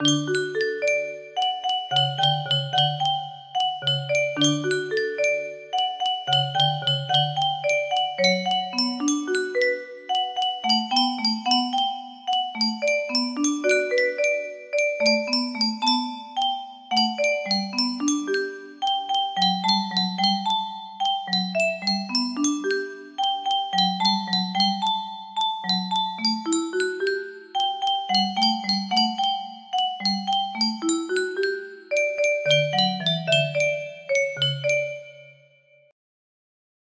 test 2 music box melody
Full range 60